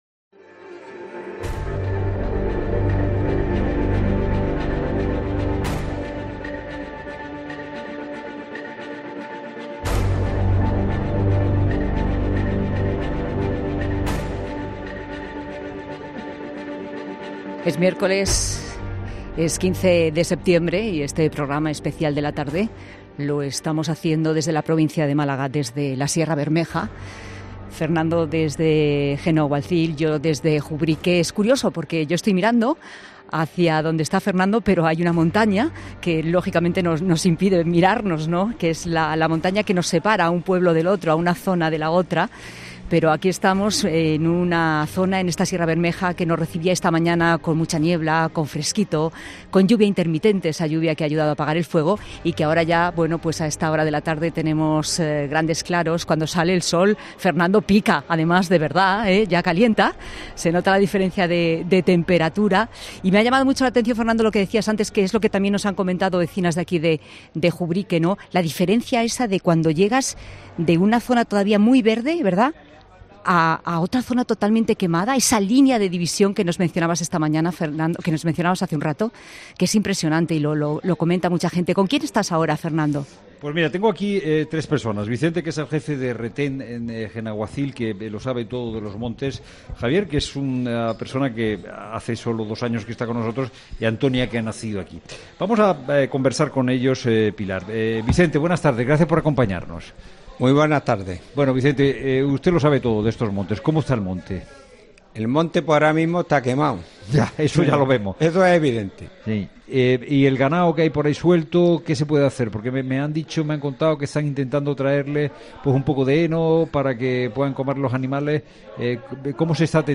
AUDIO: Desde los pueblos de Jubrique y Genalguacil, contamos con la mirada de vecinos y bomberos para analizar las vivencias y la última hora del...